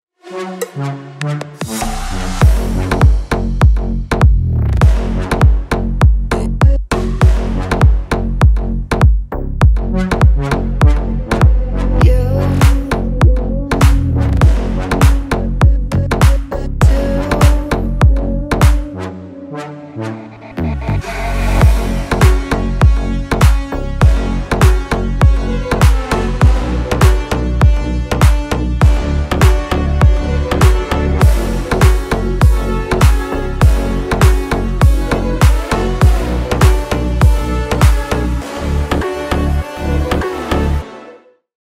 • Качество: 320, Stereo
deep house
восточные мотивы
Electronic
EDM
мощные басы
качающие
G-House